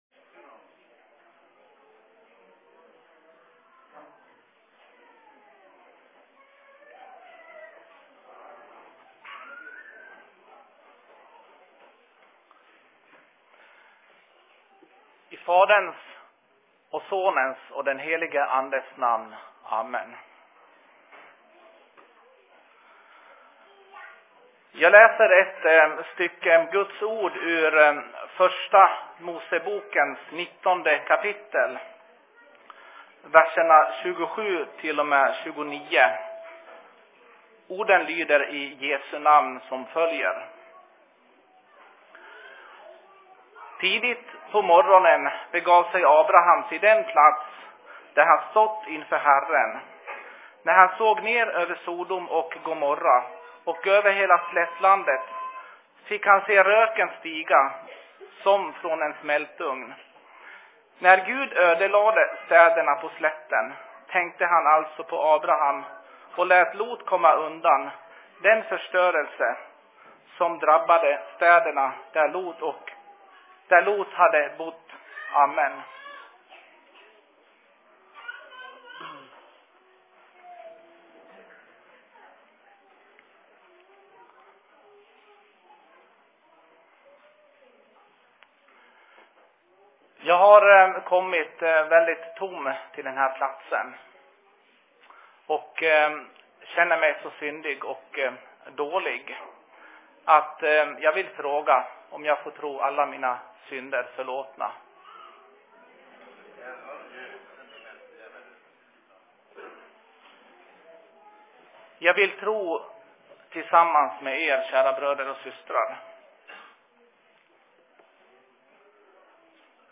Se Seurapuhe Taalainmaan RY:llä 06.01.2015
Plats: SFC Dalarna